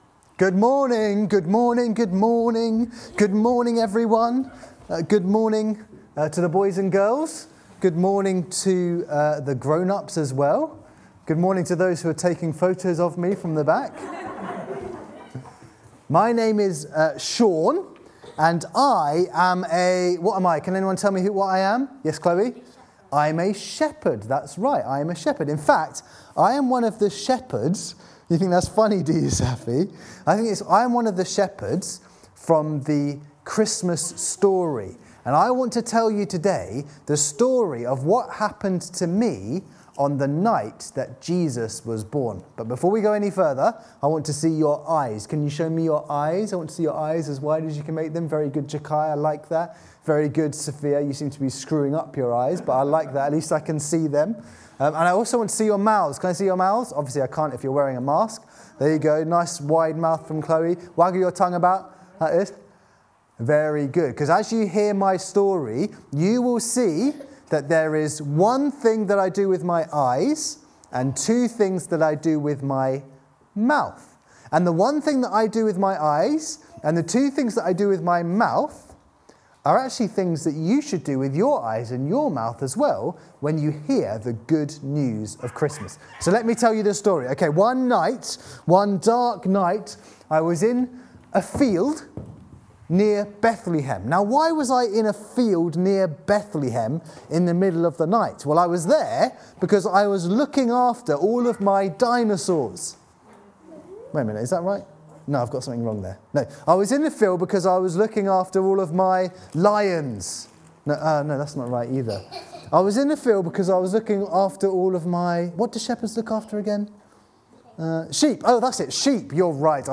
reading-and-sermon-2020-12-20.mp3